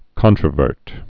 (kŏntrə-vûrt, kŏntrə-vûrt)